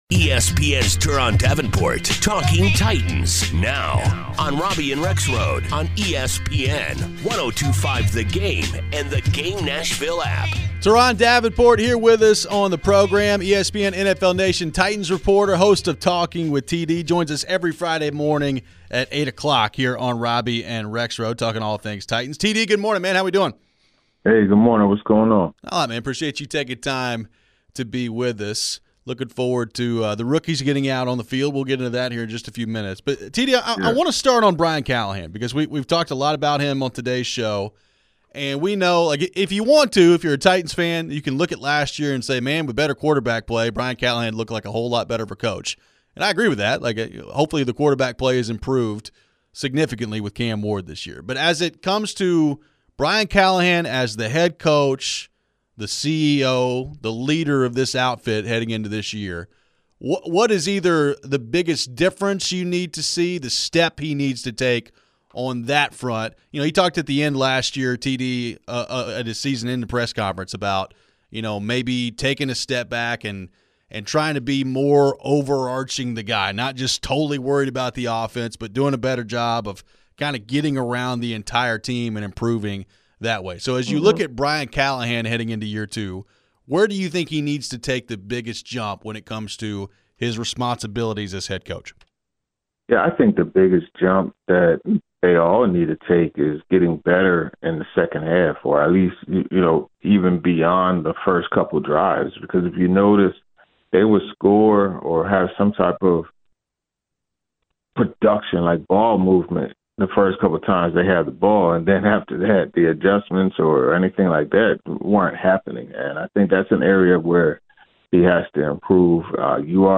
We head to your phones on the Titans.